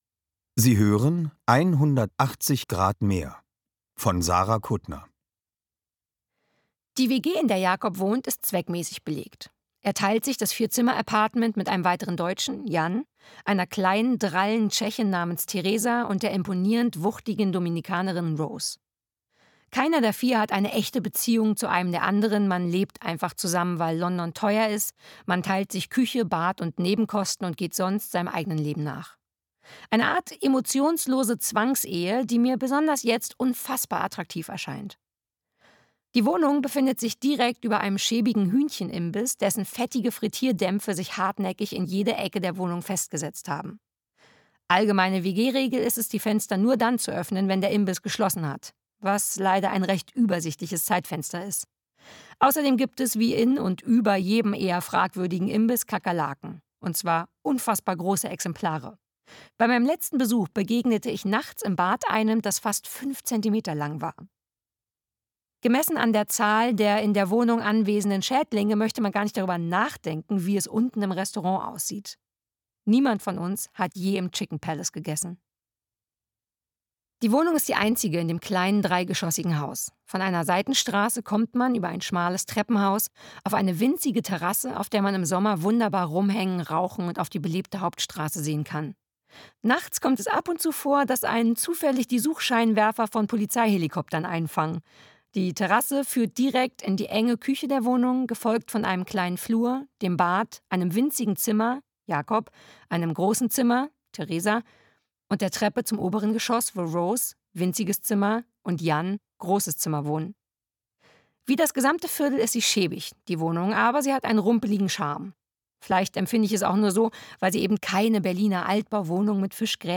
Sarah Kuttner (Sprecher)
Das neue Hörbuch von Sarah Kuttner